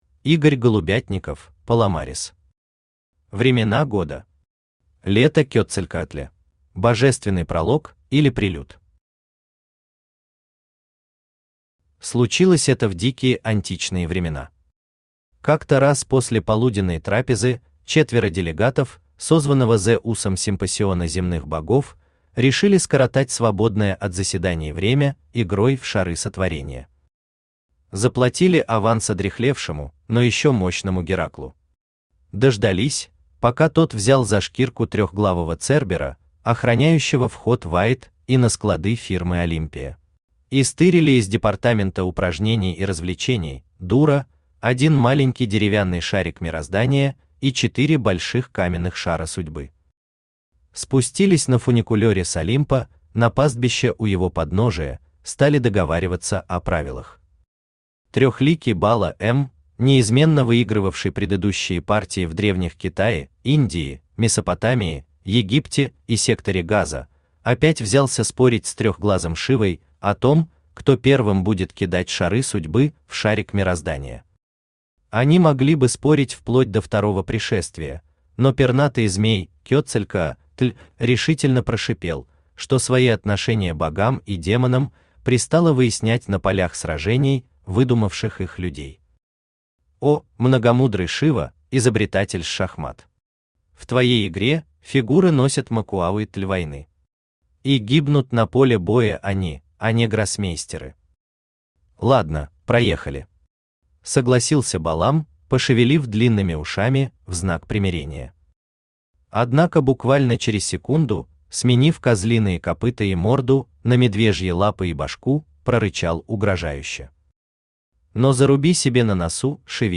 Аудиокнига Времена года. Лето Кетцалькоатля | Библиотека аудиокниг
Aудиокнига Времена года. Лето Кетцалькоатля Автор Игорь Голубятников (Паломарес) Читает аудиокнигу Авточтец ЛитРес.